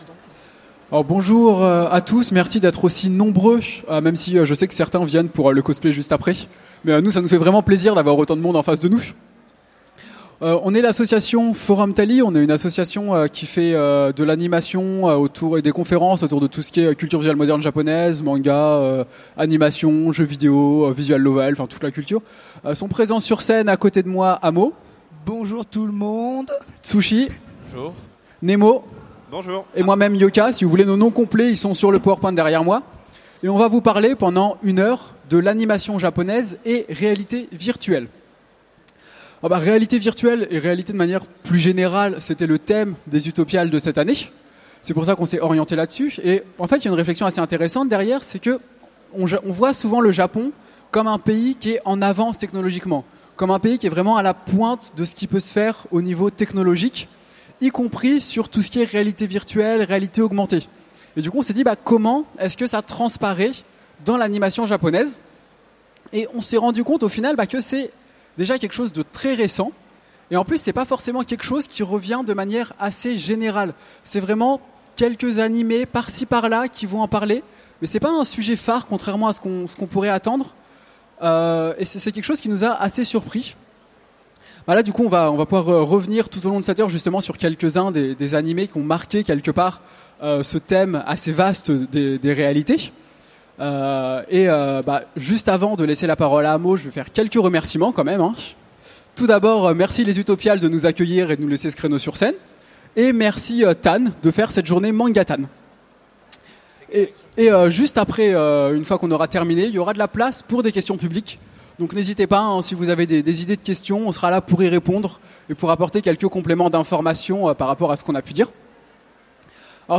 Utopiales 2015 : Conférence Animation japonaise et réalités virtuelles